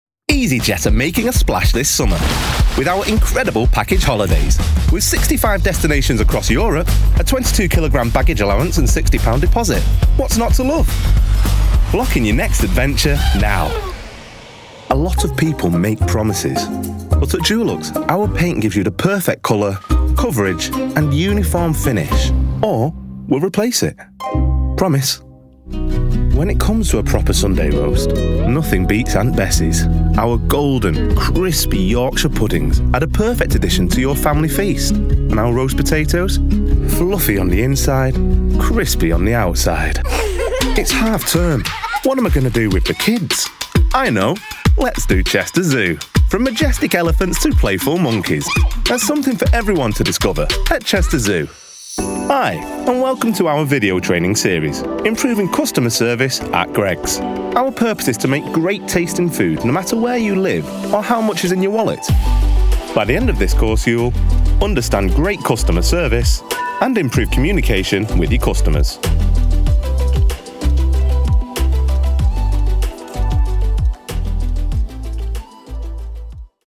Commercial / E-Learning